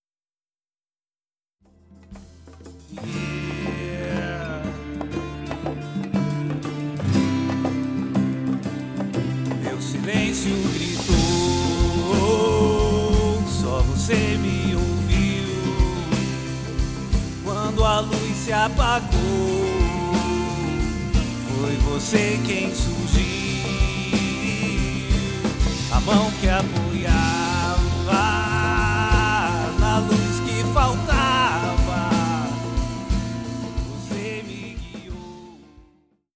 Rock Umbanda